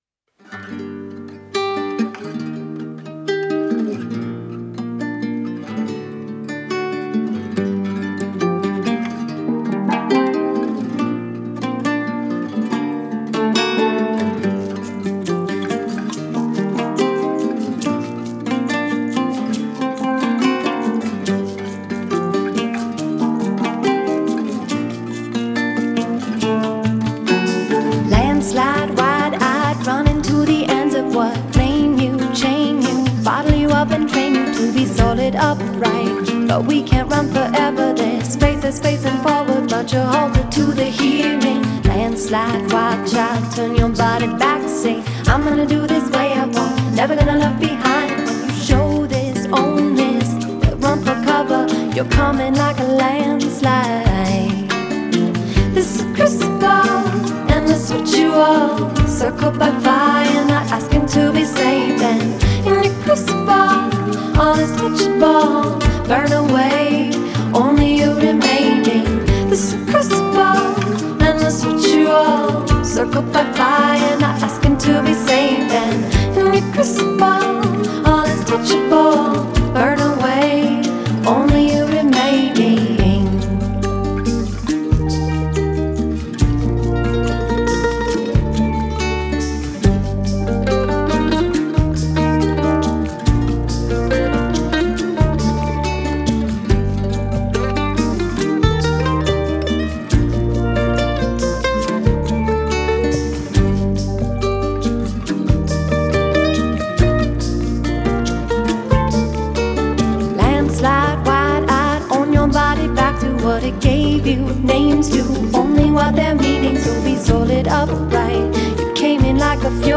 très belle version acoustique